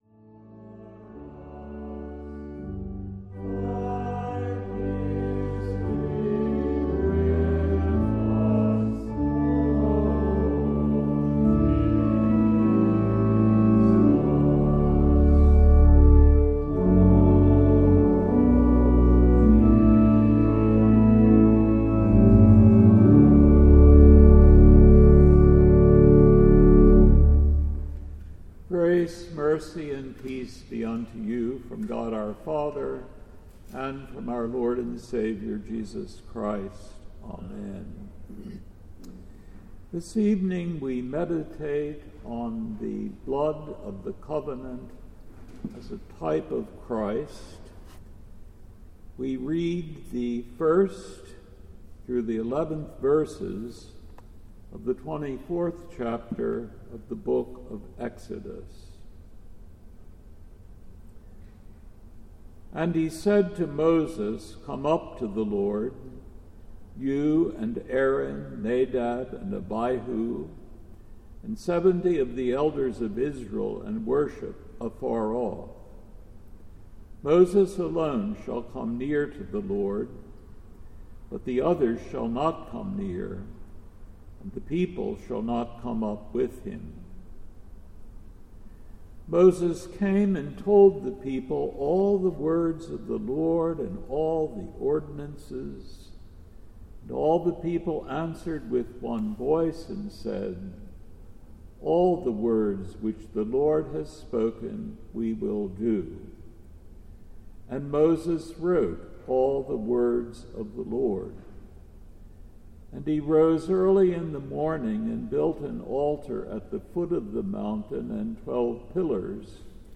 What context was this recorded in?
Laetare Midweek Vespers